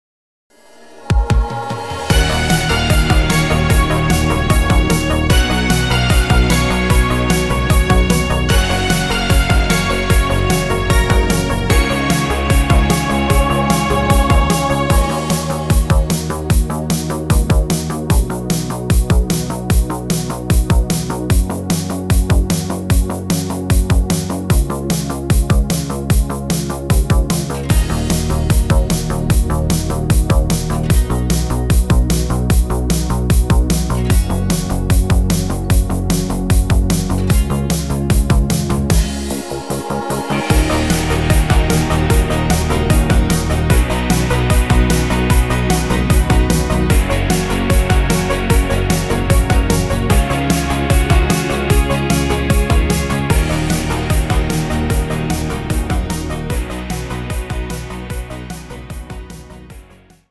echt fetziger Titel